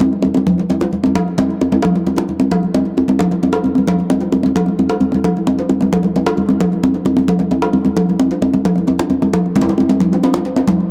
CONGABEAT7-L.wav